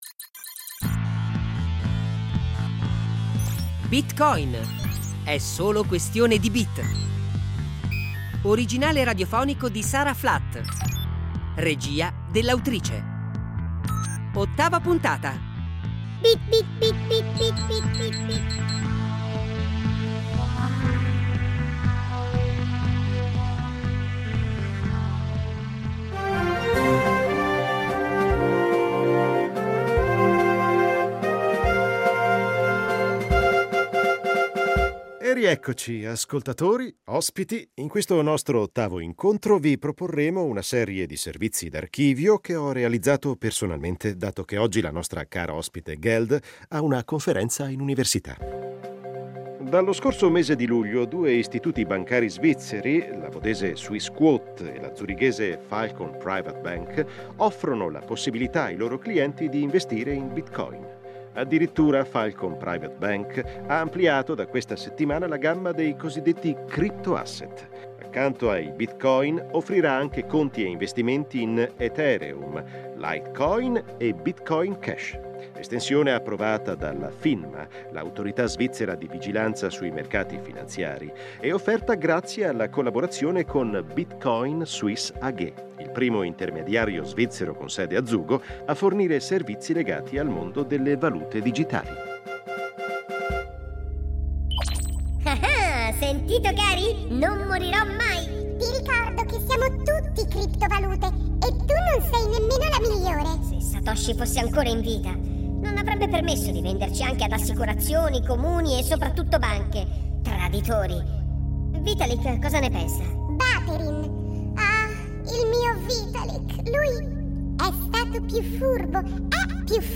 È una sfida, non una pretesa esaustiva, quella che la prosa di Rete Due cerca di affrontare, proponendo una versione a tratti documentaria, a tratti totalmente immaginaria della storia della nascita e lo sviluppo dei famosi primi bit-coin.